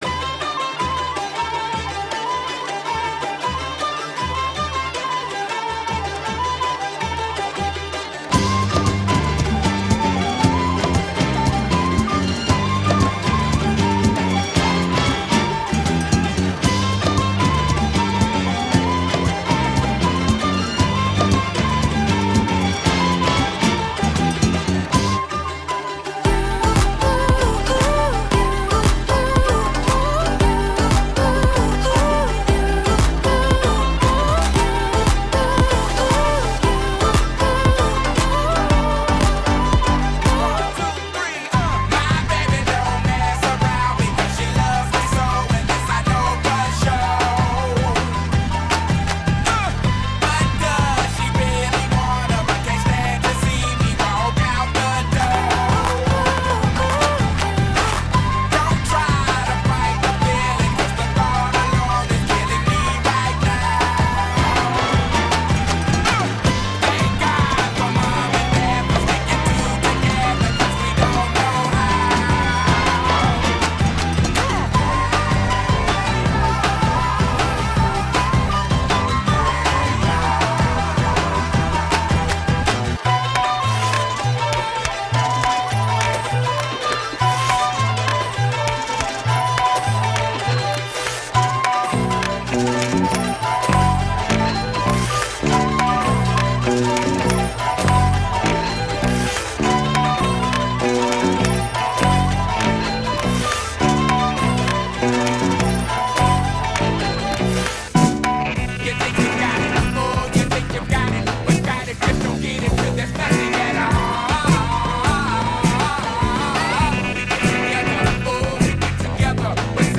13-across-8